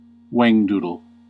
Ääntäminen
Ääntäminen US US : IPA : /ˈwæŋ.du.dəl/ IPA : /ˈweɪŋ.du.dəl/